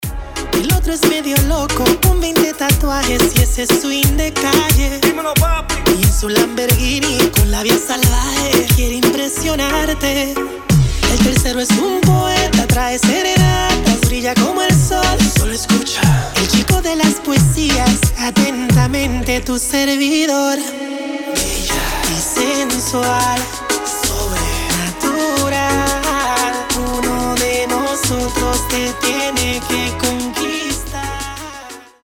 • Качество: 320, Stereo
мужской вокал
зажигательные
Latin Pop